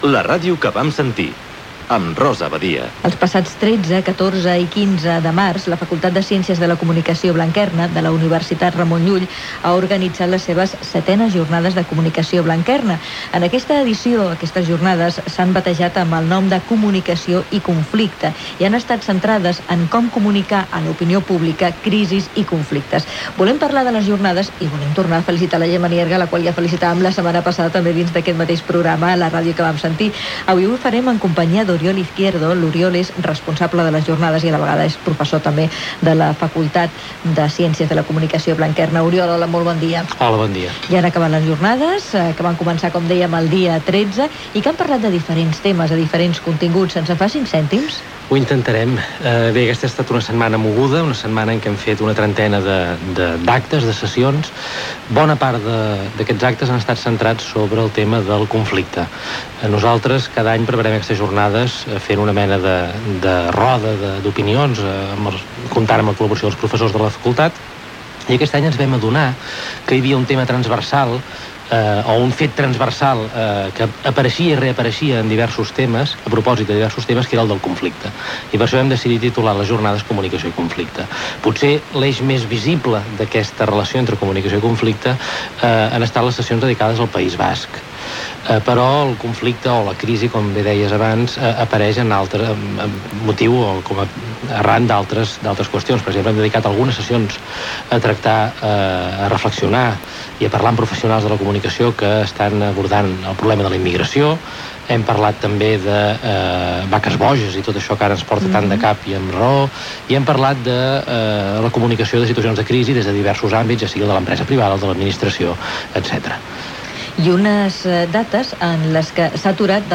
Entrevista
Divulgació